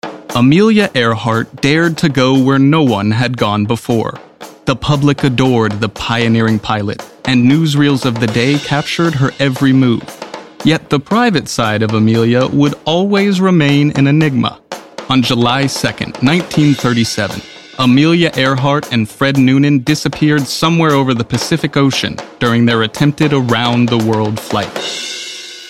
Amelia Earhart - Narration - Storytelling